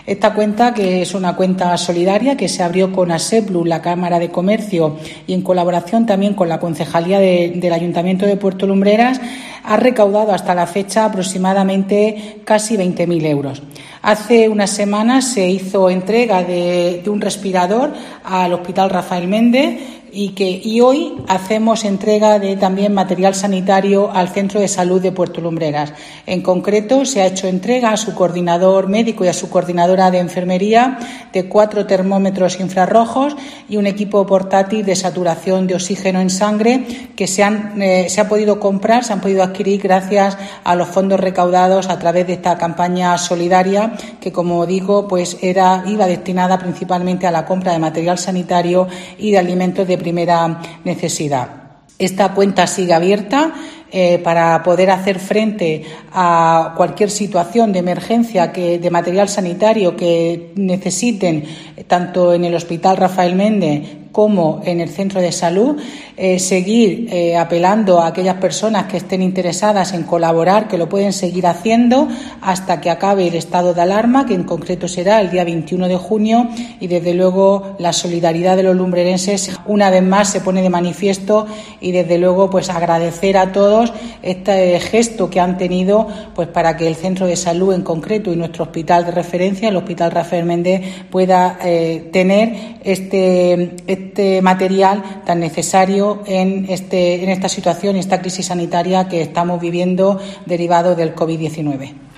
María Ángeles Túnez, alcaldesa Puerto Lumbreras sobre campaña solidaria